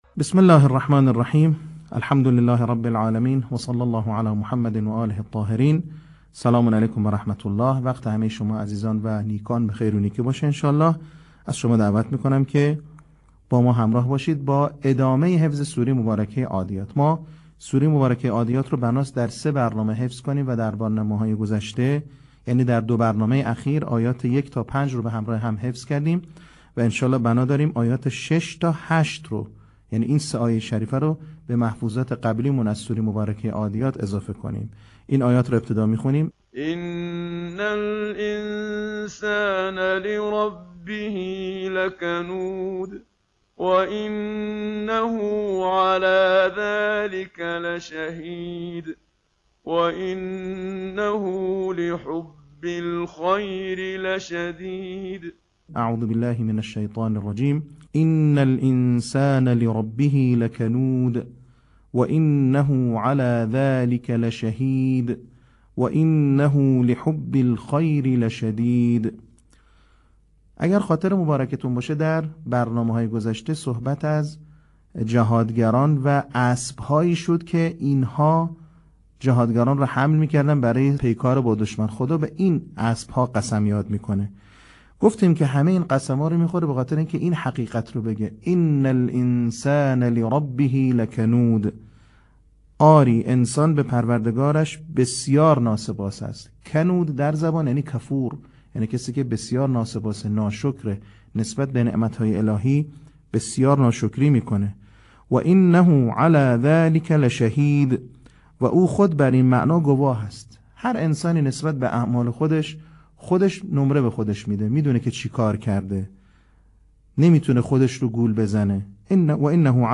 صوت | بخش سوم آموزش حفظ سوره عادیات